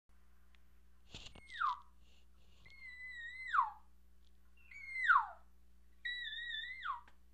Calf Elk Sounds Lost Mew The Elk calves make some of the same sounds as the cows. The difference is the higher pitch and shorter duration than the cow sounds.
lost_mew.wma